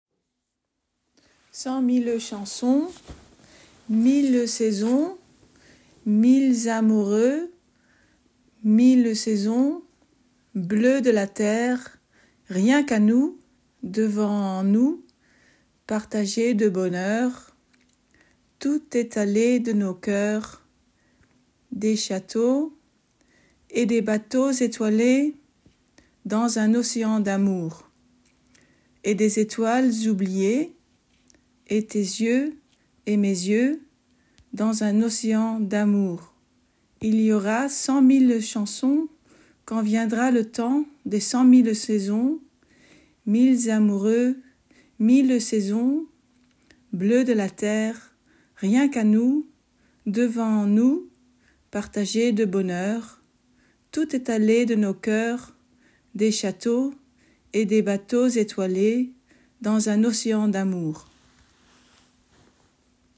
uitspraak